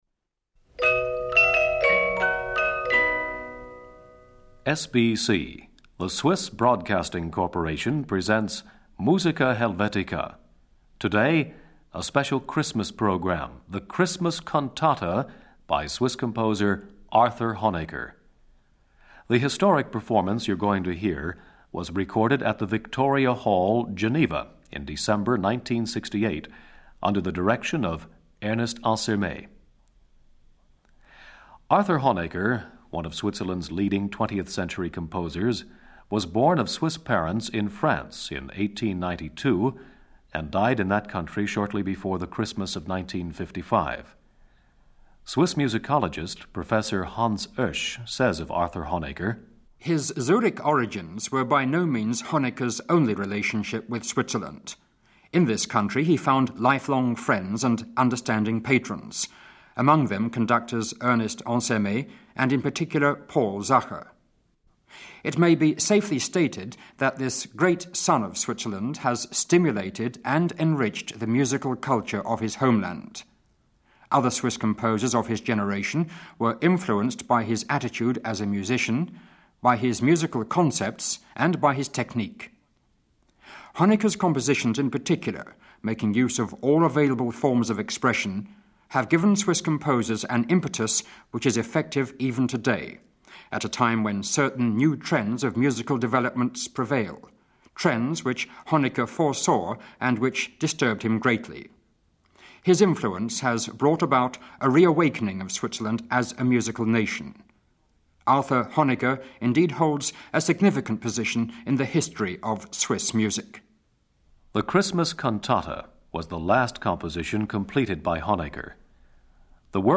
Baritone.
organ.
Orchestre de la Suisse Romande. Ernest Ansermet, conductor. Recorded by French-Swiss Radio at the Victoria Hall, Geneva, 18 December 1968. Last public concert by Ernest Ansermet.